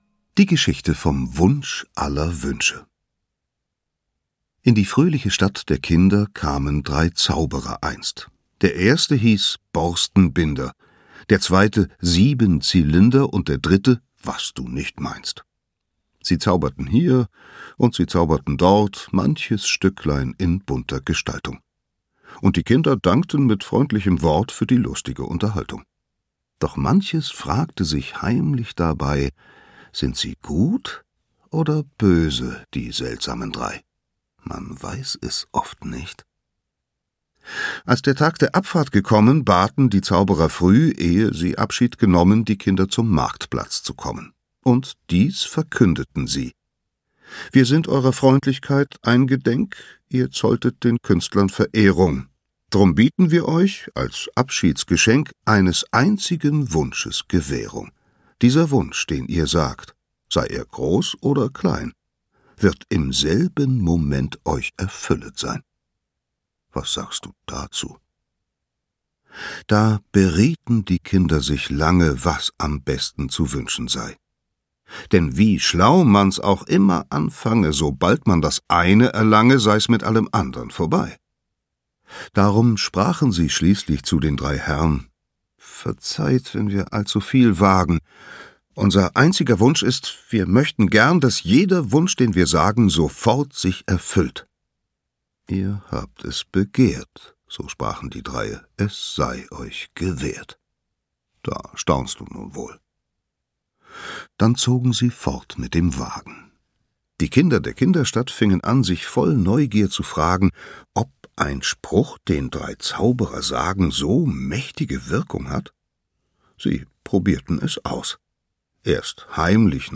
Hörbuch Das Schnurpsenbuch, Michael Ende.